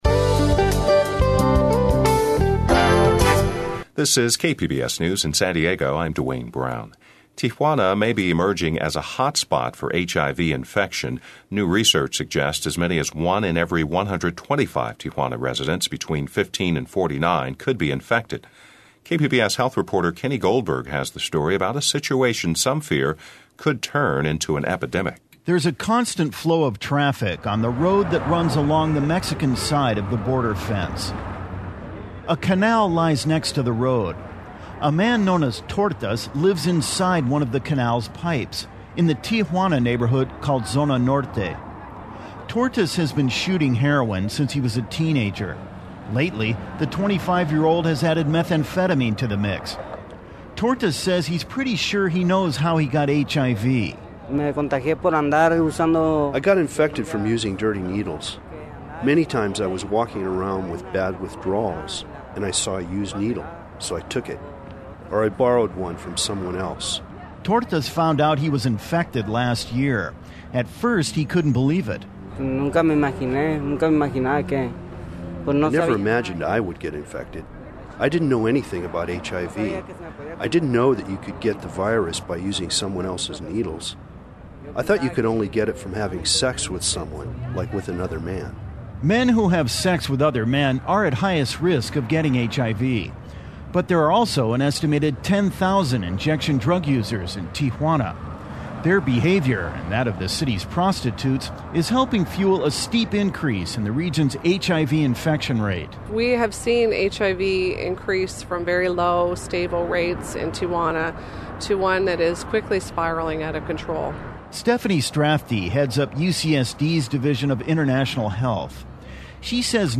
Here is a story that aired this morning on KPBS Radio – MP3 Download: